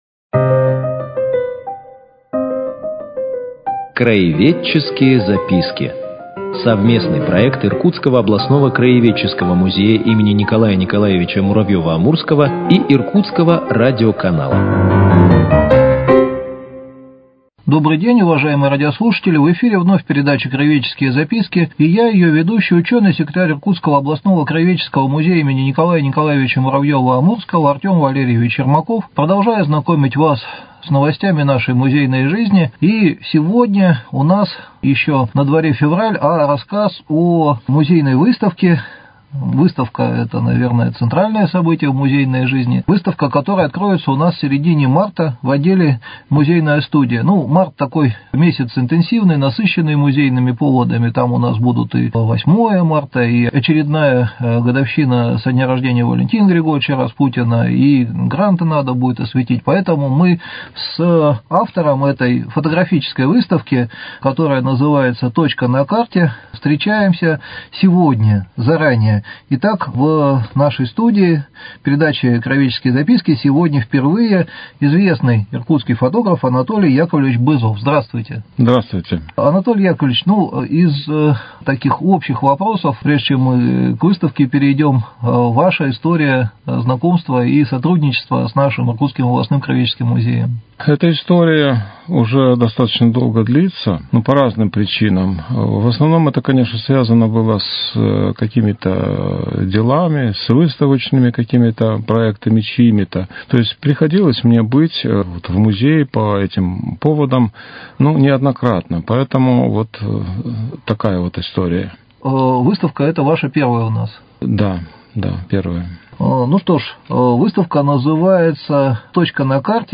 Мы предлагаем вниманию слушателей цикл передач – совместный проект Иркутского радиоканала и Иркутского областного краеведческого музея.